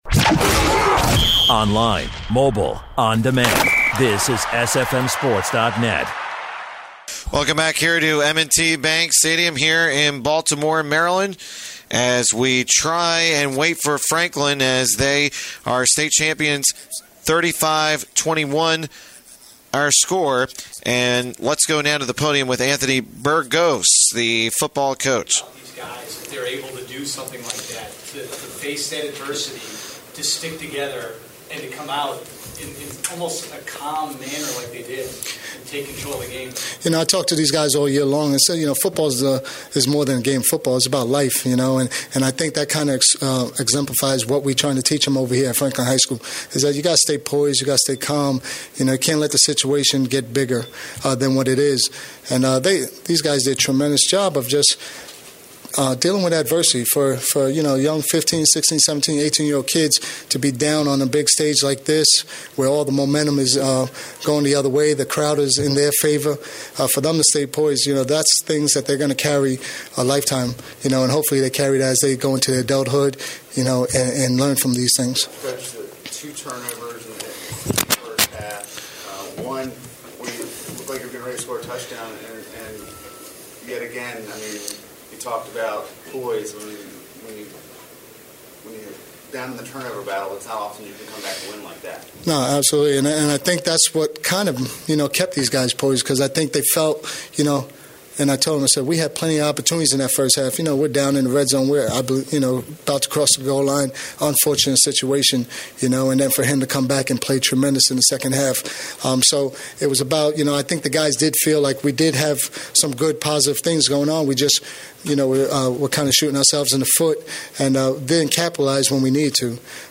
3A: Franklin Football Post Game Press Conference
The Franklin Indians repeat a state championship, this time with a 35-21 decision versus Damascus. After the game, the team reacts to the win.